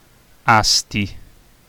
Asti (UK: /ˈæsti/ AST-ee, US: /ˈɑːsti/ AH-stee;[3][4][5] Italian: [ˈasti]
It-Asti.ogg.mp3